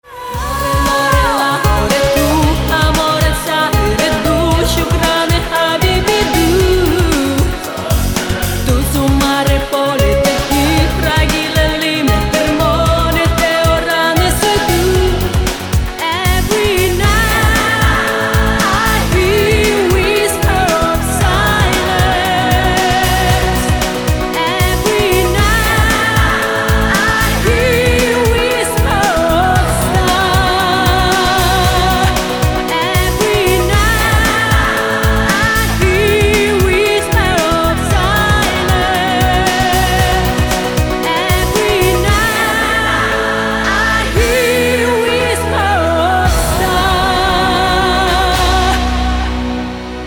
• Качество: 256, Stereo
красивые
женский вокал
мелодичные
dance